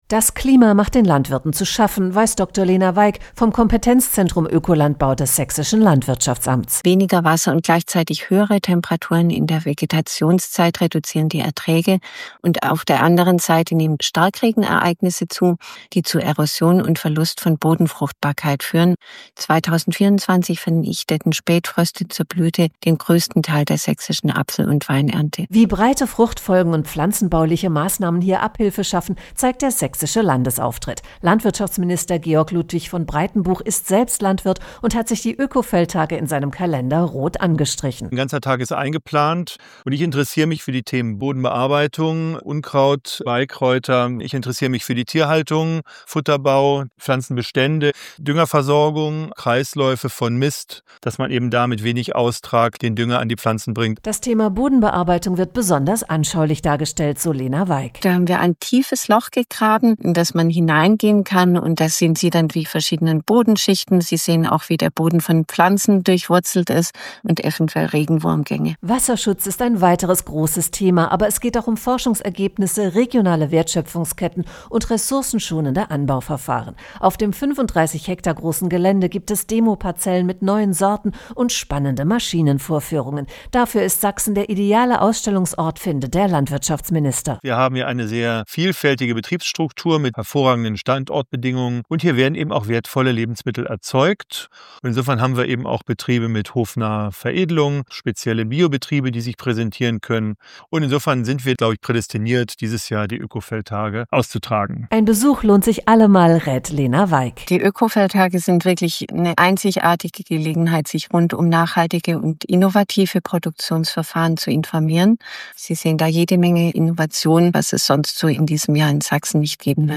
Radiopressebeitrag
01_Beitrag_OekoFeldtage.mp3